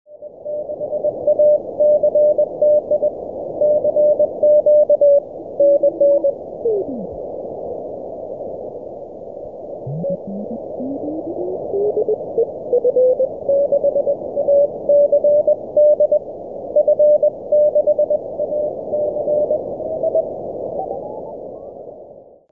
Centred on 600 Hz, its performance was excellent – indeed, almost over-kill! Here’s a sample of it, on 7 MHz in this January afternoon, with the TS590 on full CW bandwidth, & RF gain about halfway: